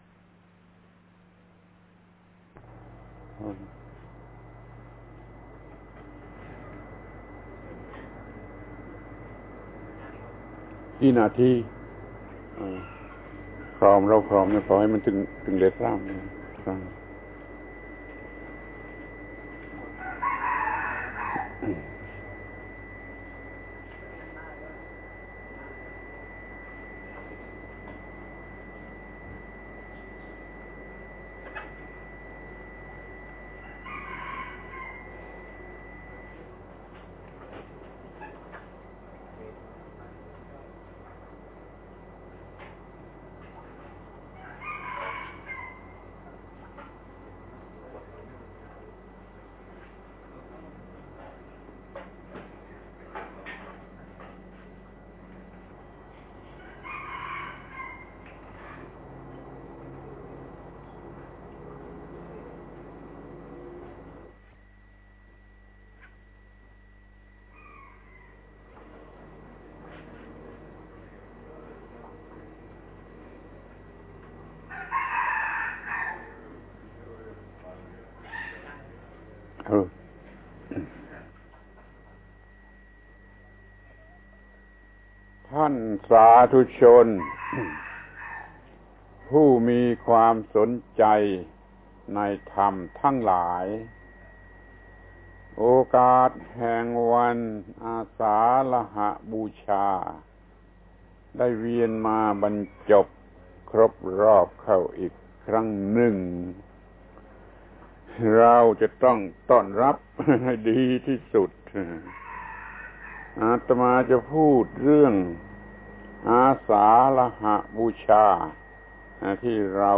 พระธรรมโกศาจารย์ (พุทธทาสภิกขุ) - ปาฐกถาธรรมทางโทรทัศน์พิเศษวันอาสาฬหบูชา